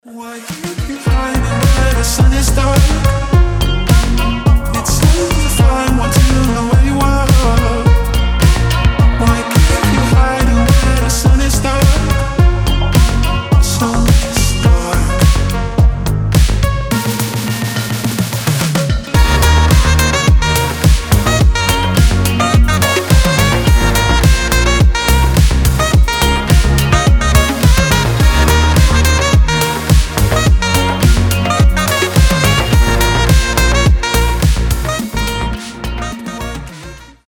• Качество: 320, Stereo
мужской голос
Dance Pop
Саксофон
house